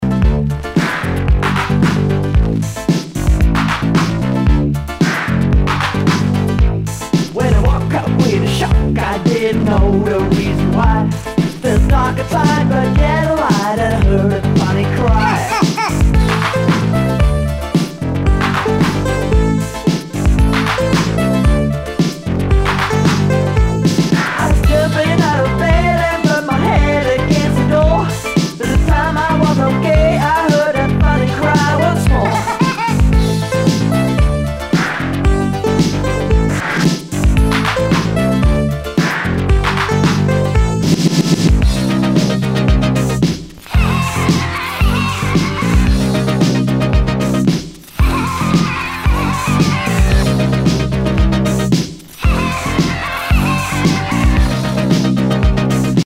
SOUL/FUNK/DISCO
ナイス！シンセ・ポップ・ディスコ！
全体に大きくチリノイズが入ります